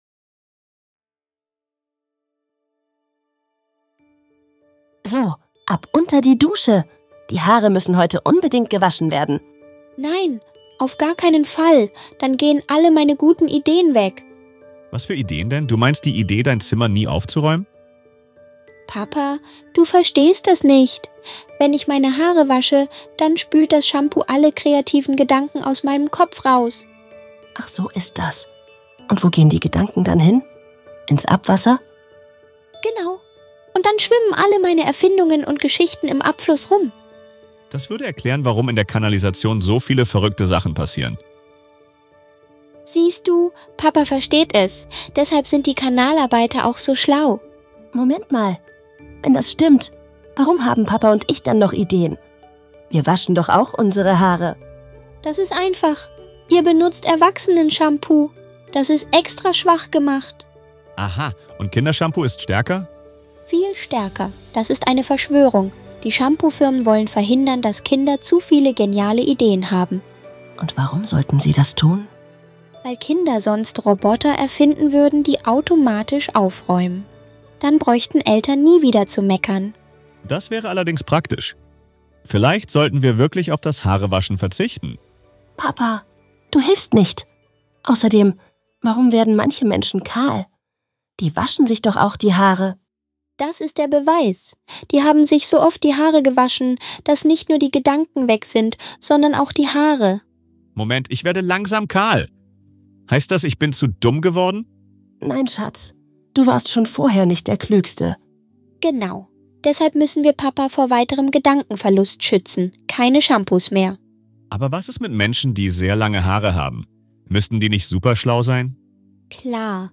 diesem lustigen Gespräch diskutieren sie: • Warum Kinder-Shampoo